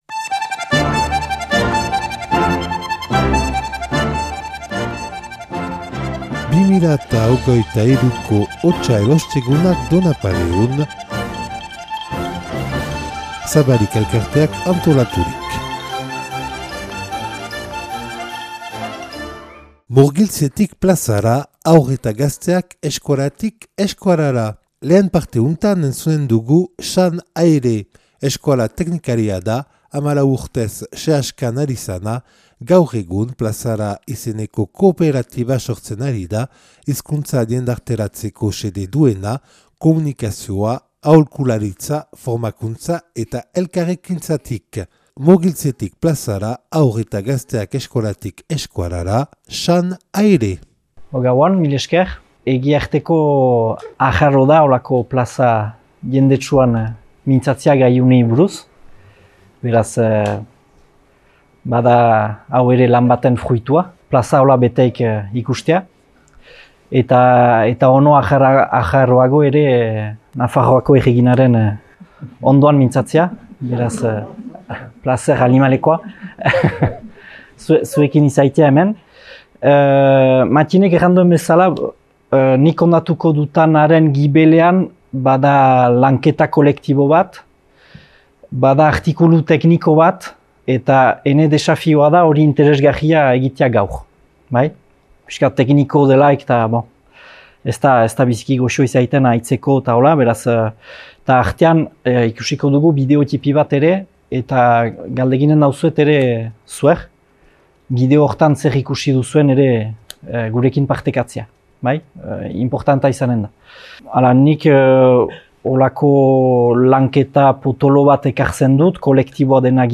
(Donapaleun grabatua 2023. otsailaren 16an).